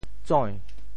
“指”字用潮州话怎么说？
tsoi~2.mp3